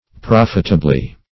Prof"it*a*bly, adv.